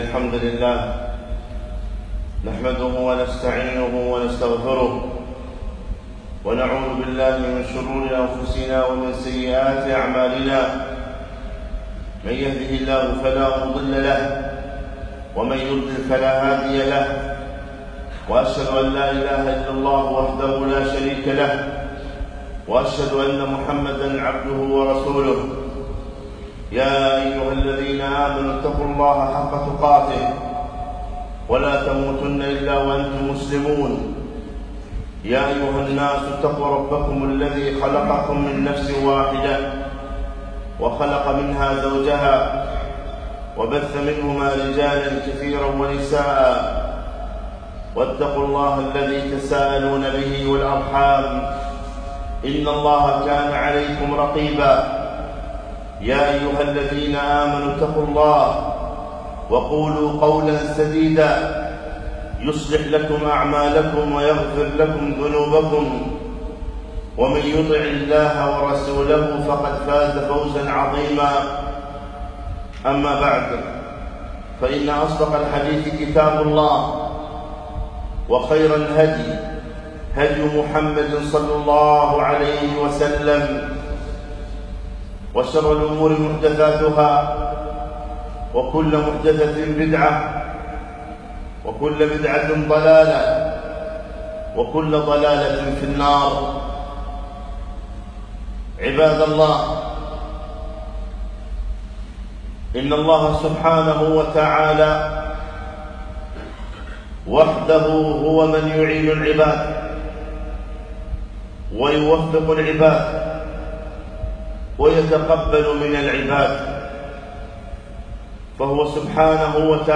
خطبة - هل تحمل هم القبول؟